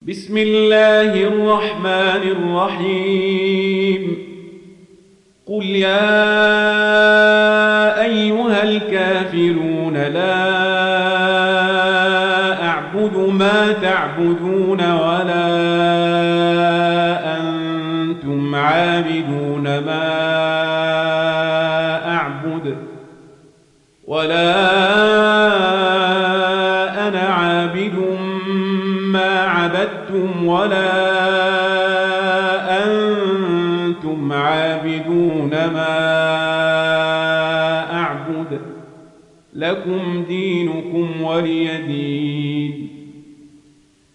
(Riwayat Warsh)